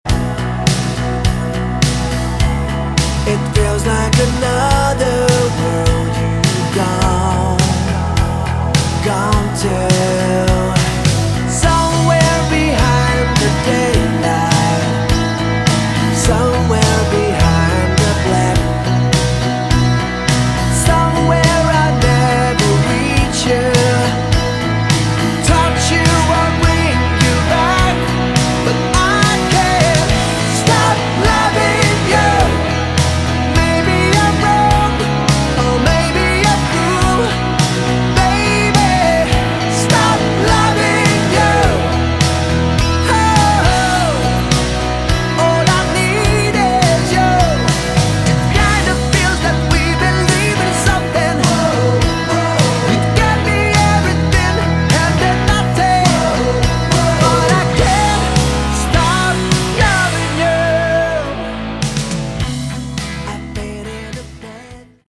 Category: AOR / Melodic Rock
vocals, guitar
bass
keyboards
drums